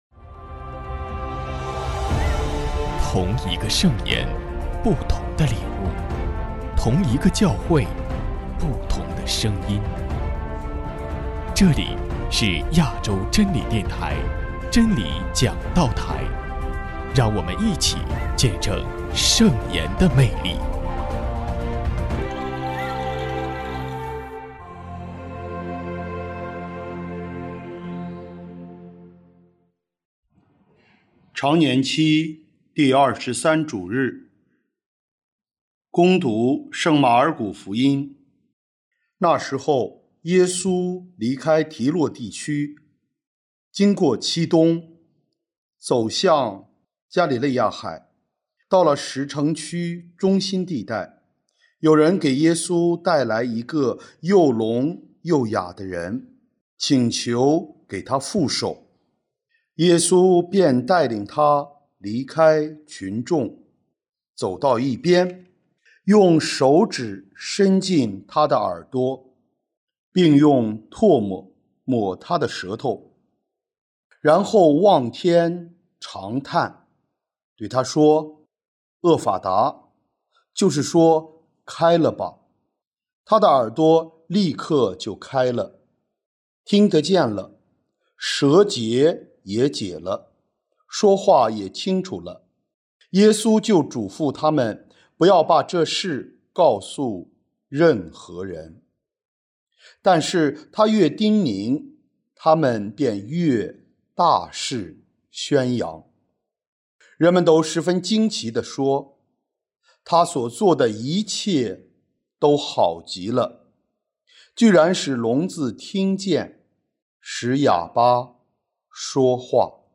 首页 / 真理讲道台/ 证道/ 乙年